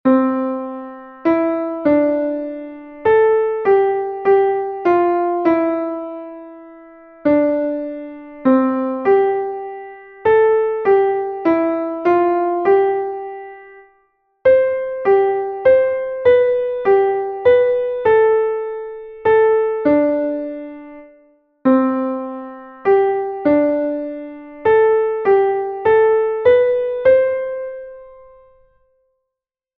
Exercise 2 5th interval practice
8._melodic_reading_practice.mp3